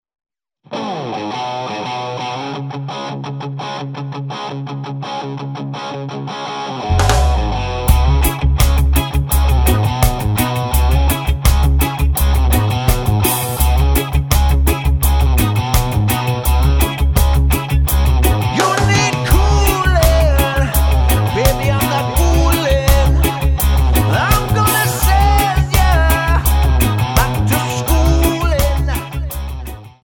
Tonart:C#m Multifile (kein Sofortdownload.
Die besten Playbacks Instrumentals und Karaoke Versionen .